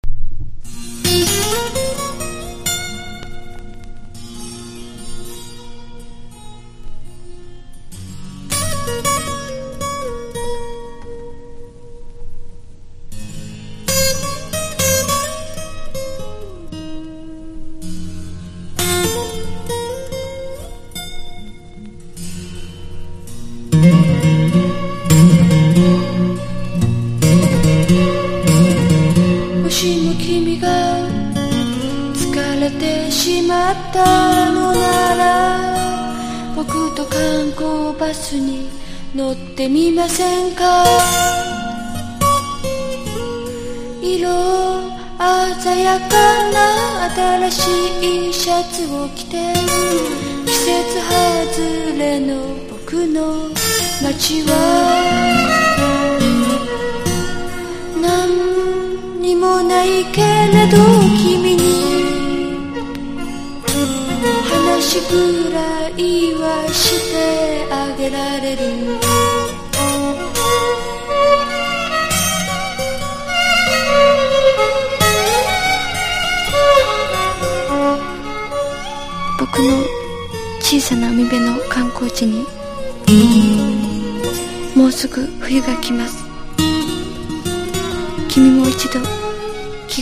一度聴いたら耳から離れない独特の歌声と歌詞、現在でも根強くファンを増やし続けるのも頷けるオリジナリティ溢れる作品。